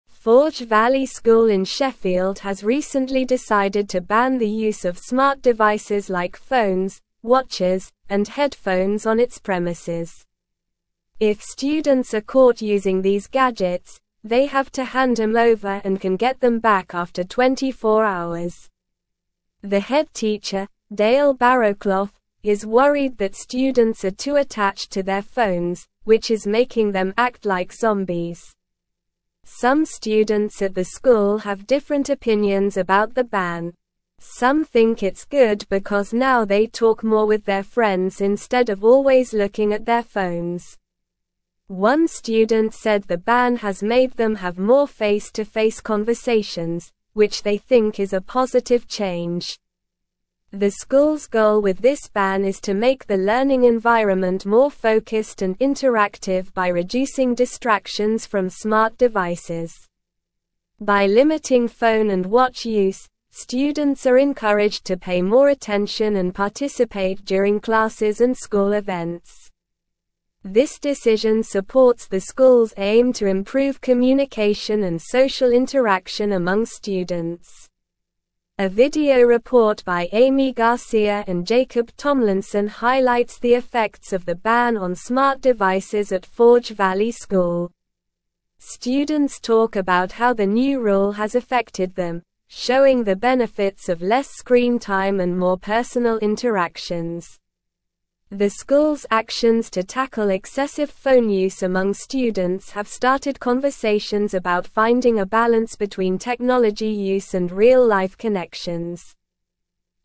Slow
English-Newsroom-Upper-Intermediate-SLOW-Reading-Forge-Valley-School-Implements-Ban-on-Smart-Devices.mp3